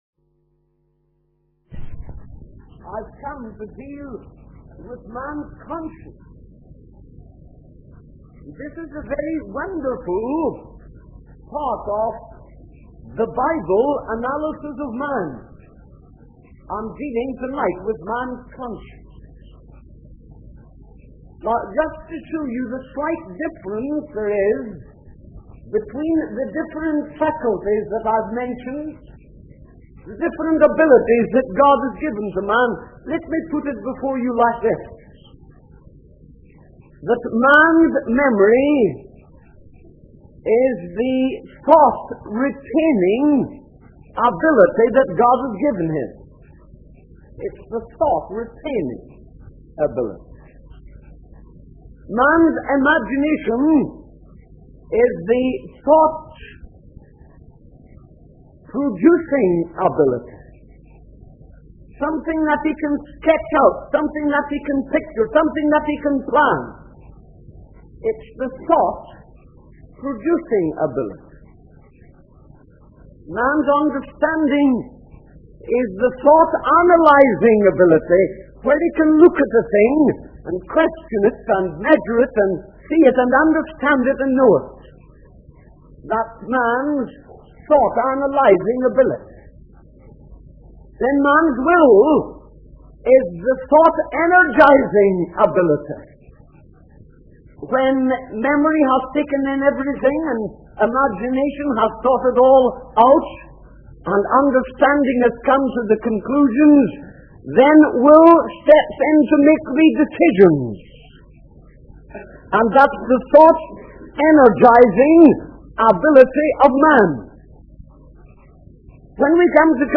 In this sermon, the preacher discusses the importance of the conscience and its role in distinguishing right from wrong.